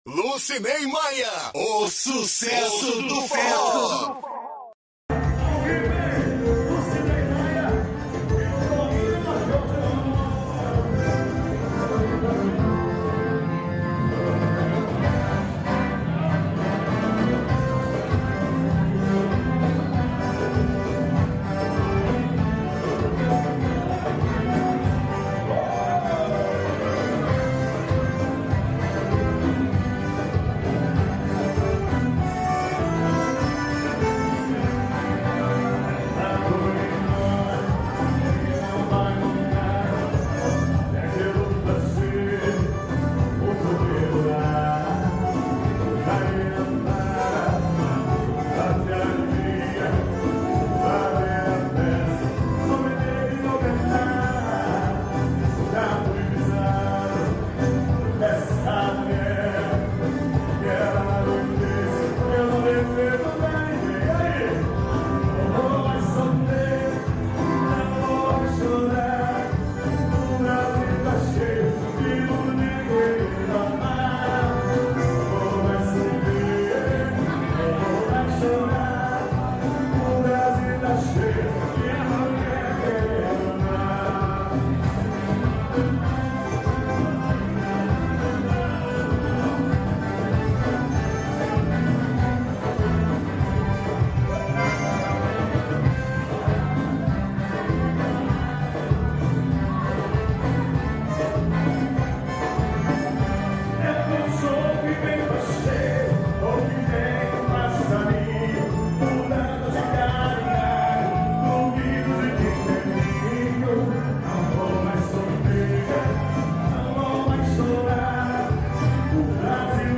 Ao vivo no Monte Castelo, Três Rios - RJ.